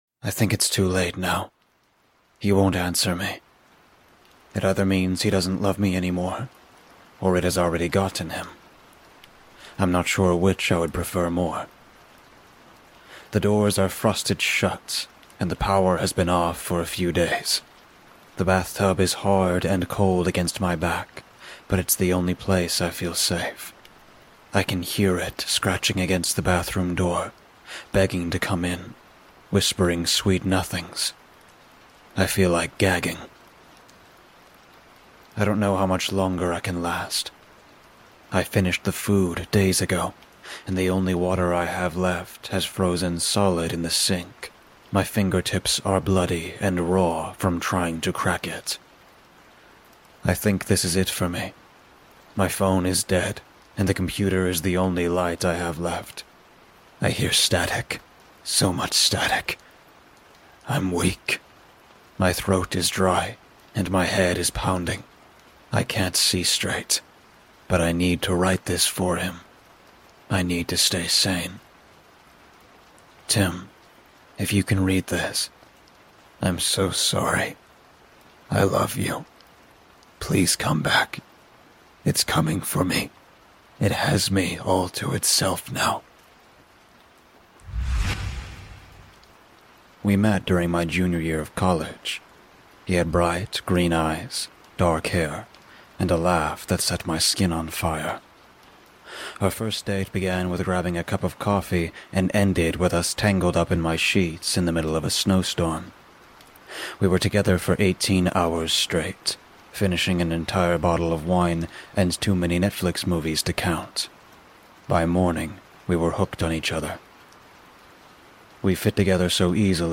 A Demon Destroyed My Relationship │NoSleep Horror Story